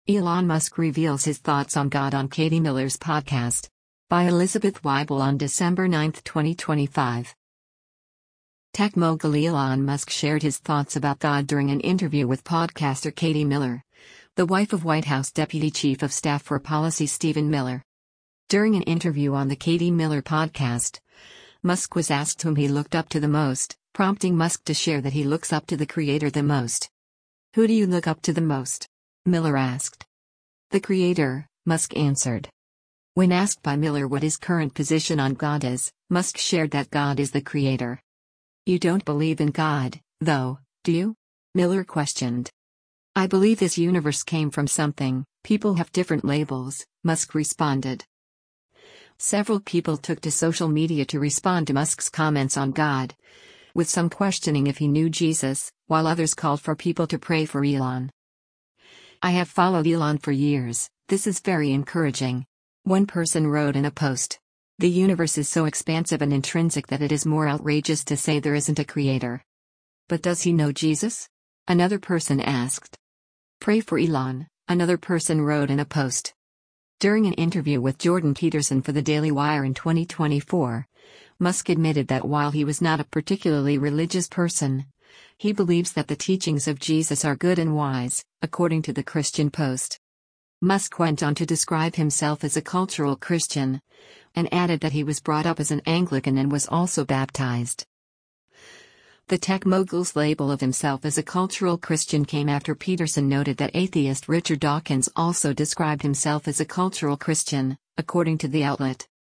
Tech mogul Elon Musk shared his thoughts about God during an interview with podcaster Katie Miller, the wife of White House Deputy Chief of Staff for Policy Stephen Miller.